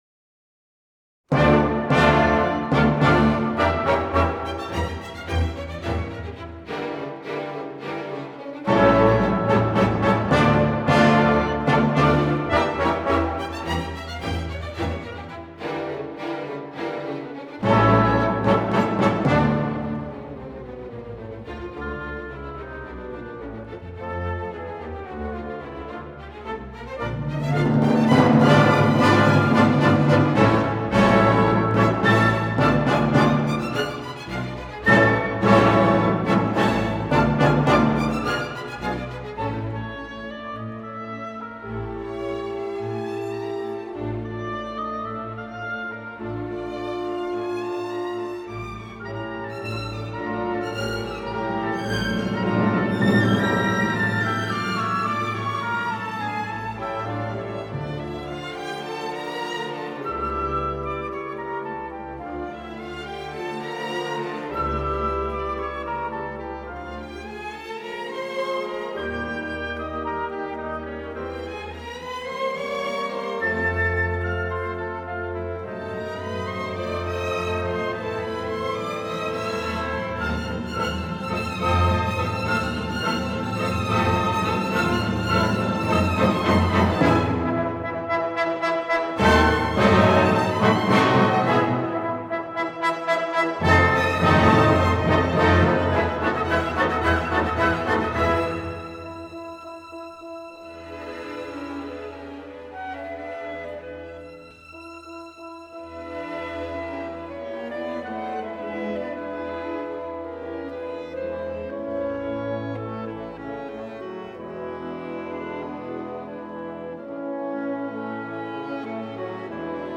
所属时期 浪漫主义